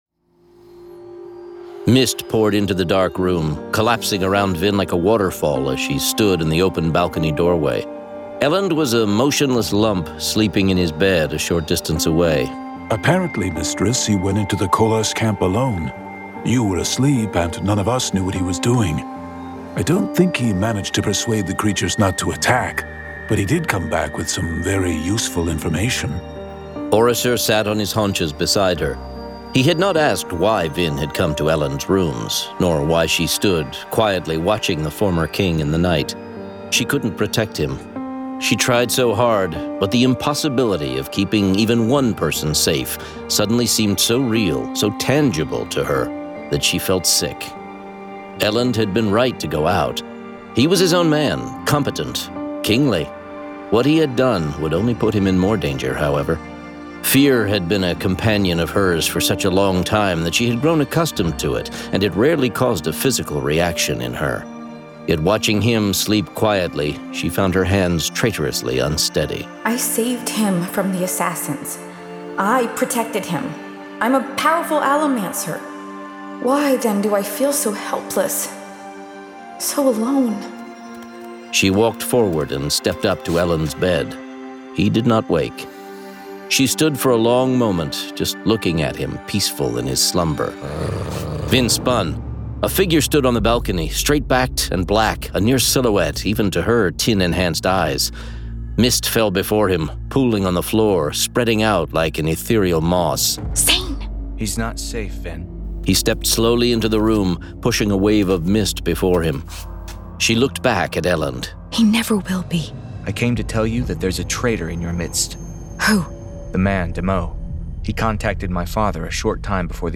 Full Cast. Cinematic Music. Sound Effects.
[Dramatized Adaptation]
Genre: Fantasy